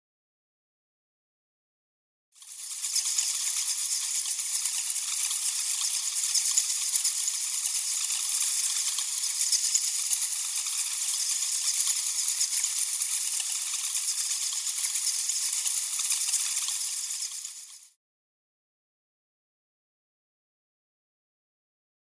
دانلود آهنگ خفاش 1 از افکت صوتی انسان و موجودات زنده
دانلود صدای خفاش 1 از ساعد نیوز با لینک مستقیم و کیفیت بالا
جلوه های صوتی